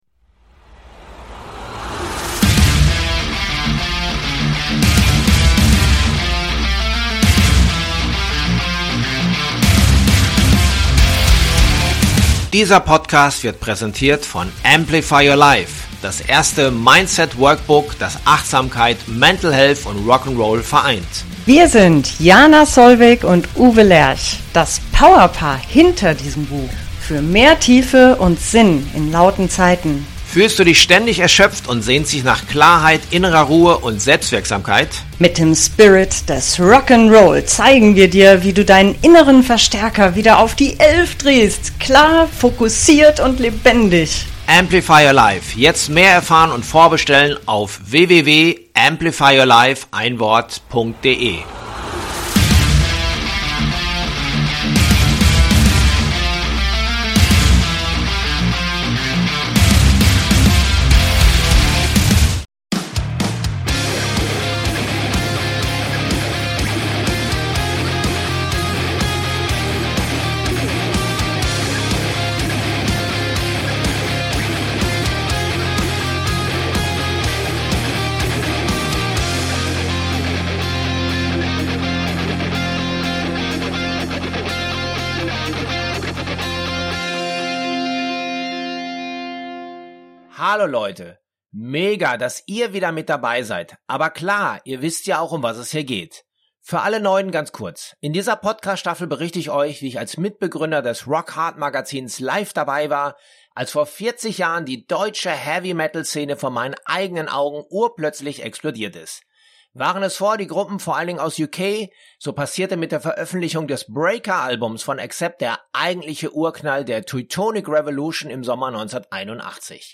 Und wenn Doro Pesch einmal in Form ist, dann ist sie kaum zu bremsen und so wurde meine Audienz in einem Hotel in der Nähe des Düsseldorfer Flughafens länger und länger.